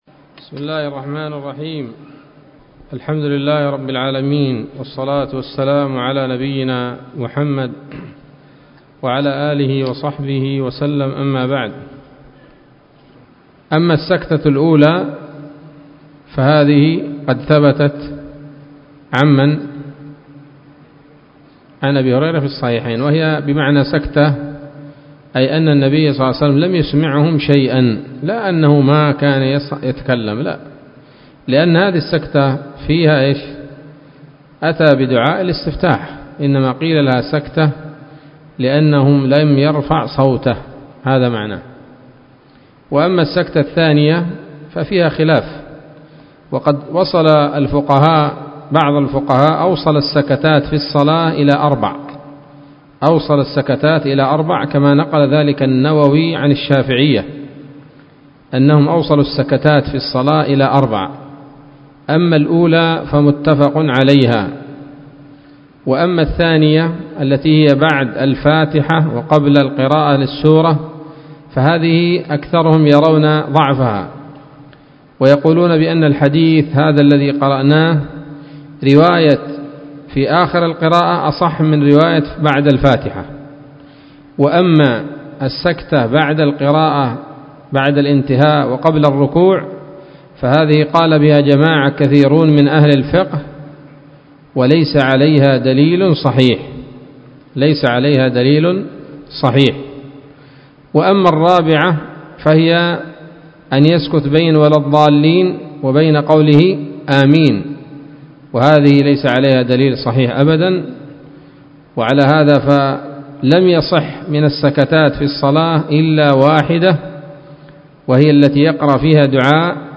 الدرس الحادي والخمسون من أبواب صفة الصلاة من نيل الأوطار